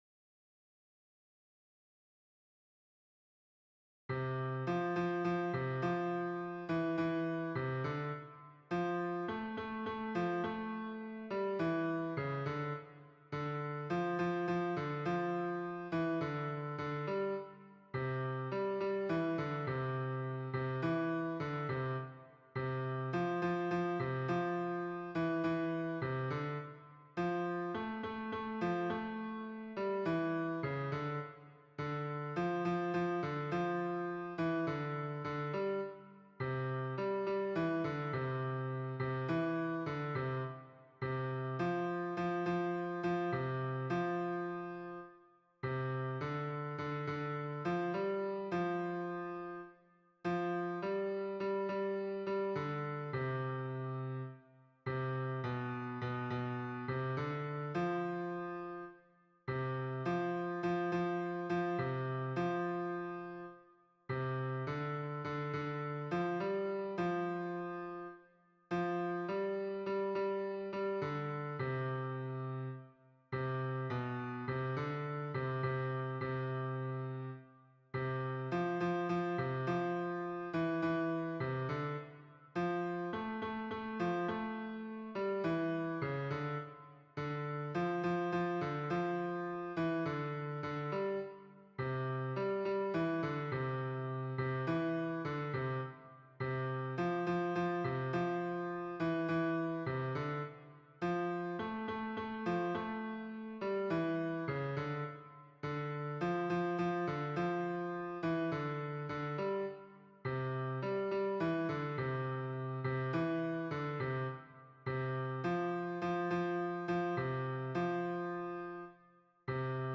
Hommes